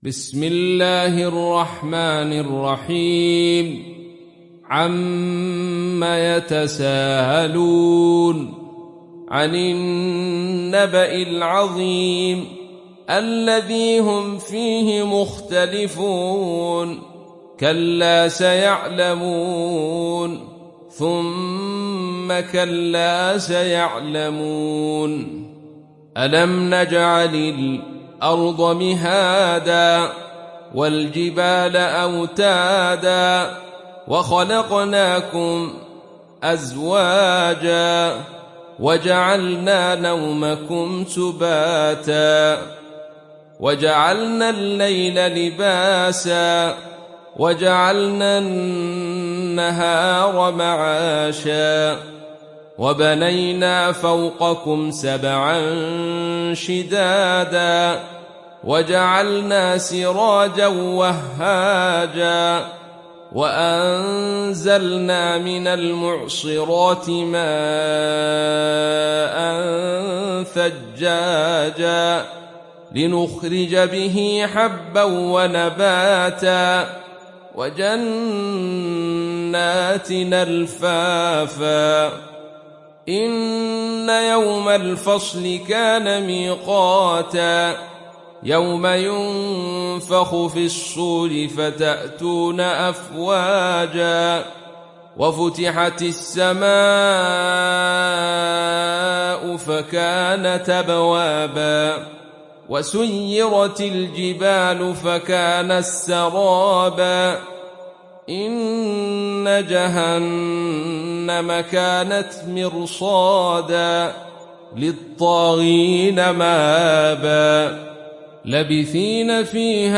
دانلود سوره النبأ mp3 عبد الرشيد صوفي روایت خلف از حمزة, قرآن را دانلود کنید و گوش کن mp3 ، لینک مستقیم کامل